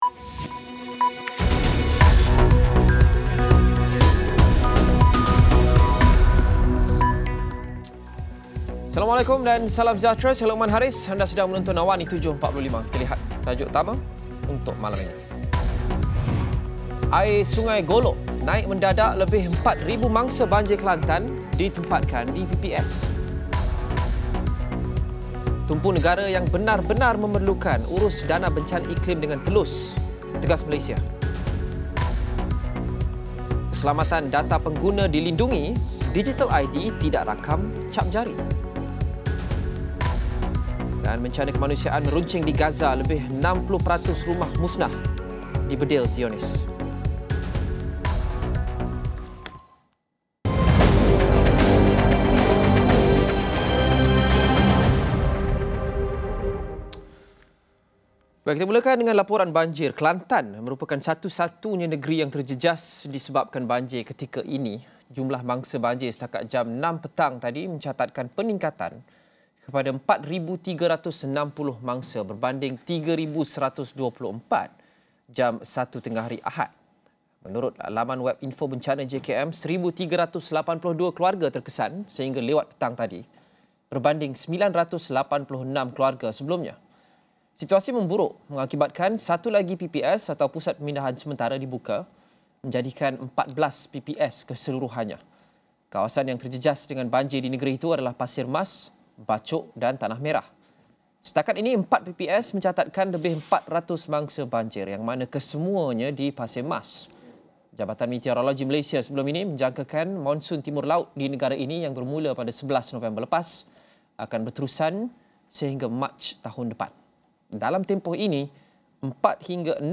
Laporan berita padat dan ringkas